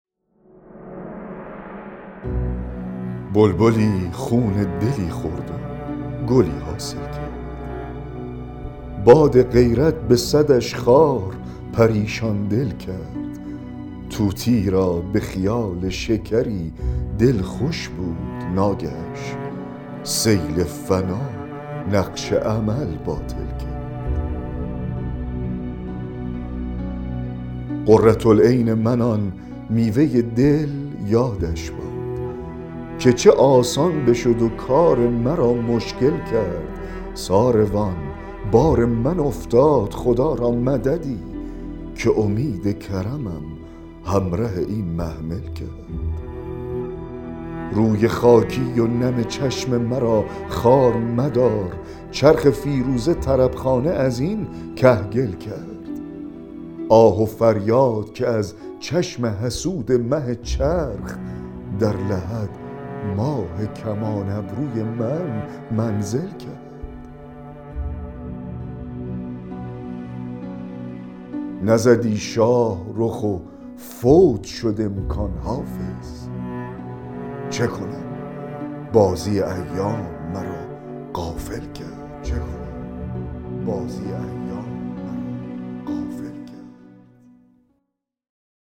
دکلمه غزل 134 حافظ
دکلمه-غزل-134-حافظ-بلبلی-خون-دلی-خورد-و-گلی-حاصل-کرد.mp3